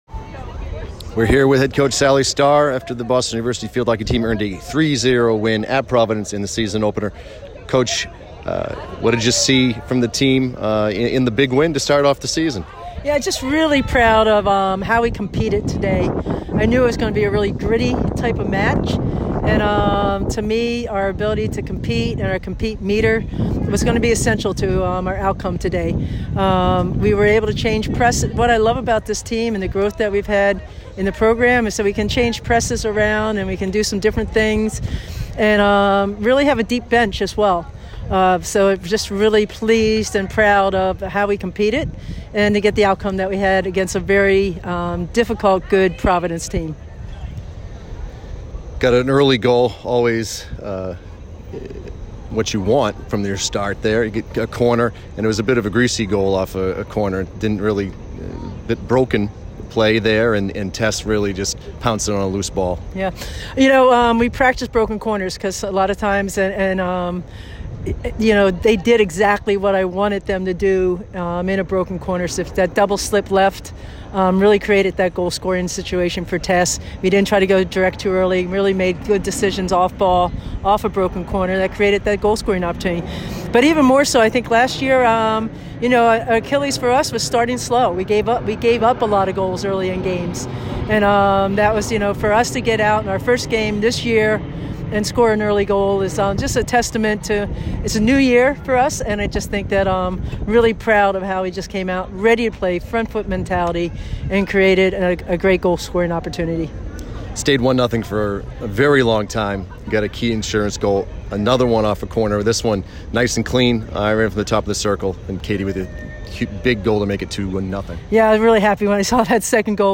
PC_Postgame.mp3